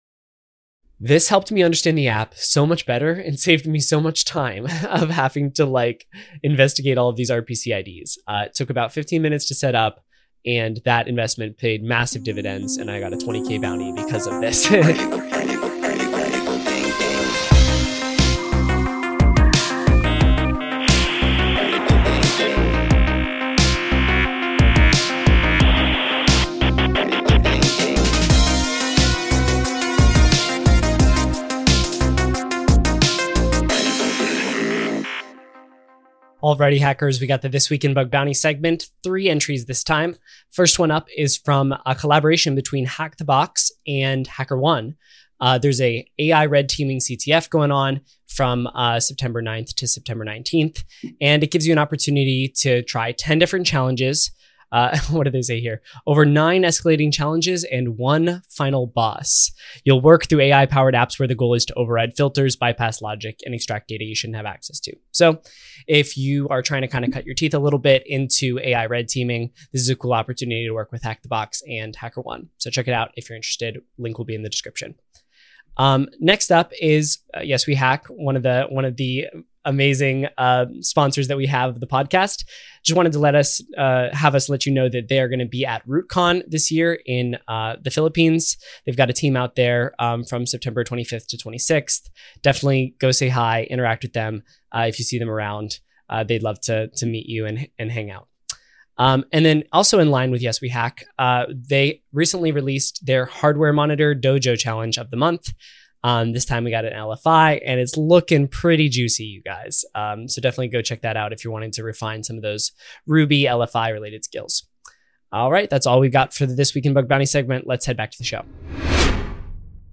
Our interviews on Entrepreneurs On Fire are meant to be evergreen, and we do our best to confirm that all offers and URL's in these archive episodes are still relevant.